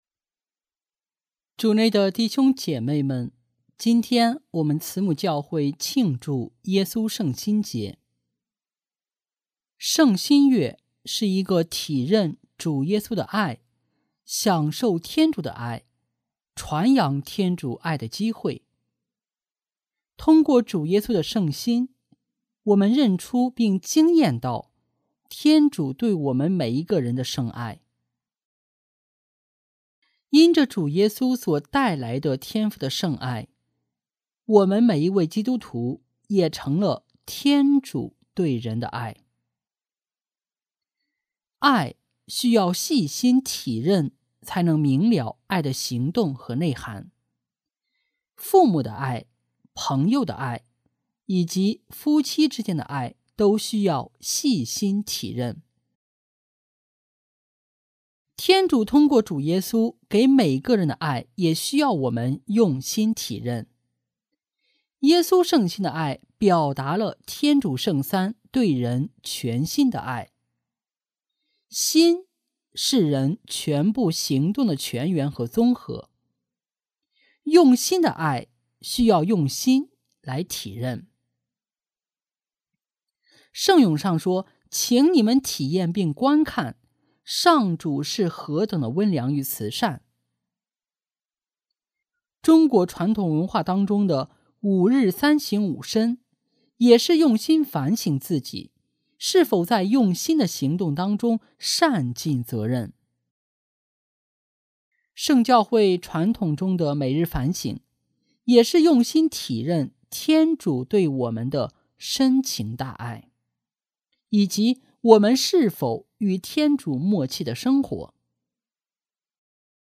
【主日证道】| 体认主爱（耶稣圣心节）